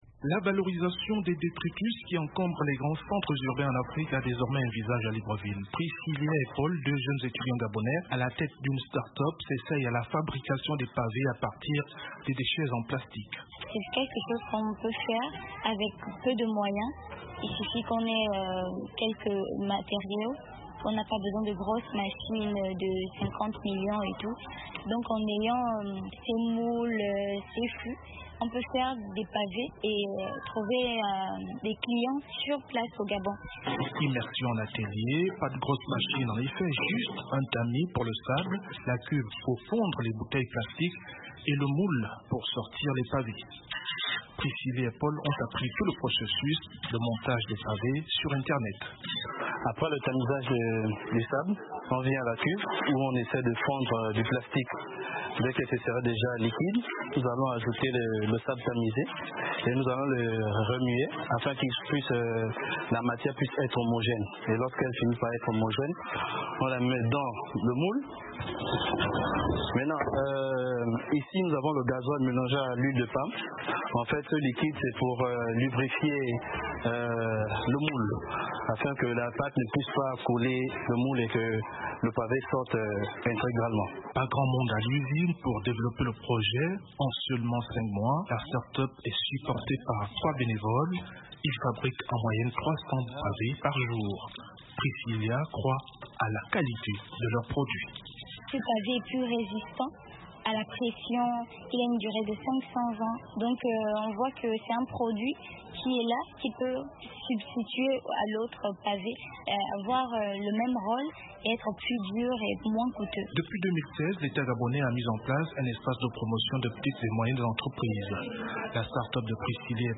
En ligne d’Abuja